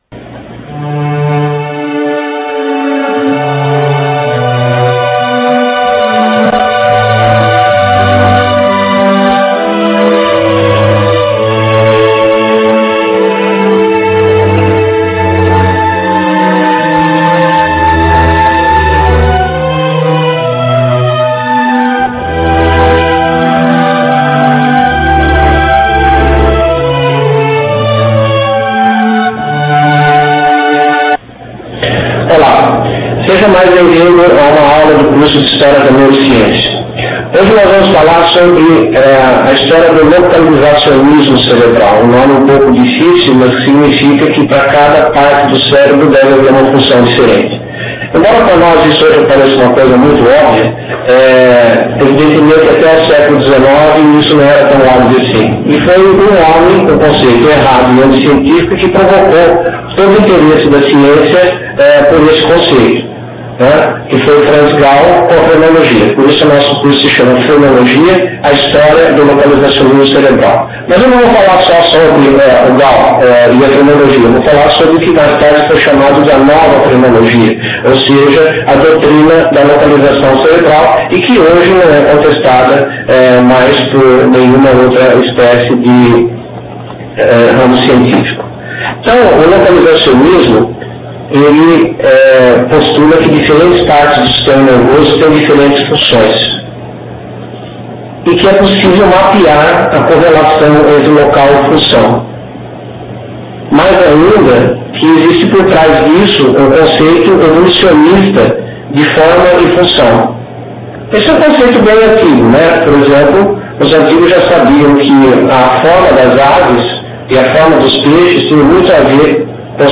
Assista a aula expositiva em rempo real, no formato de vídeo em RealMedia disponível no site do curso.